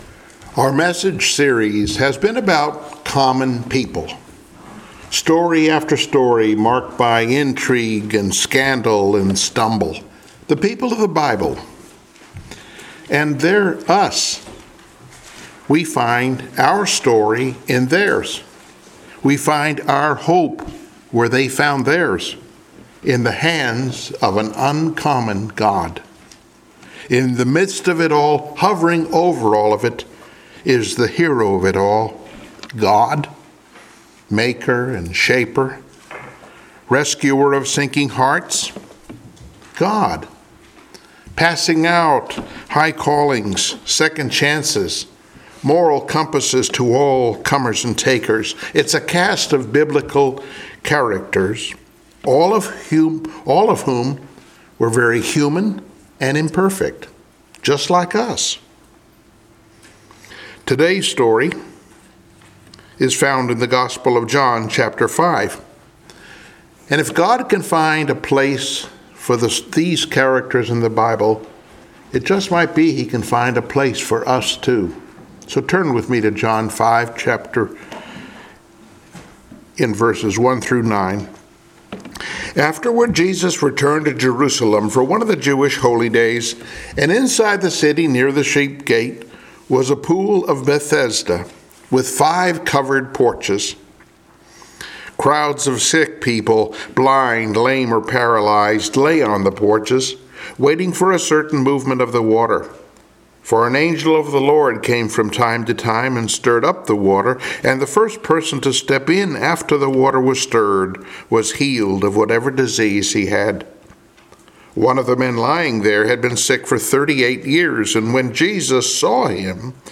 Passage: John 5:1-9 Service Type: Sunday Morning Worship